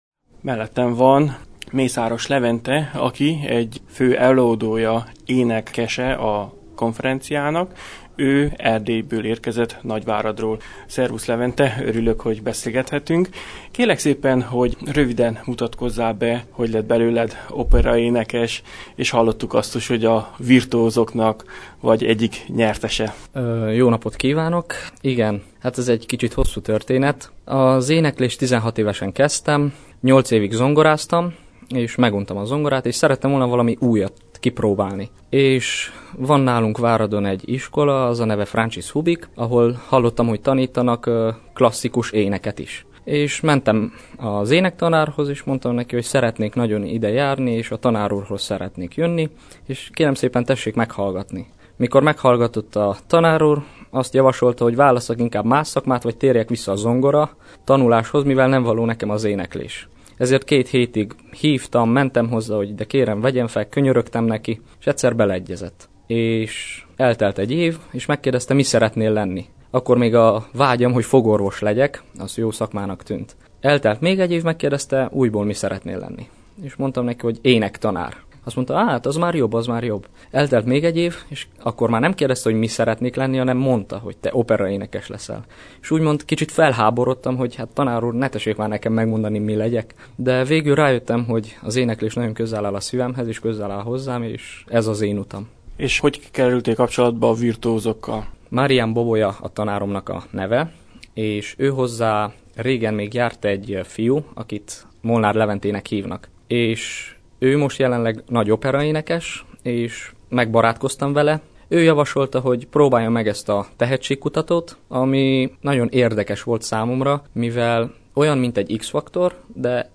Természetesen vele is készítettem egy rövid bemutatkozó interjút.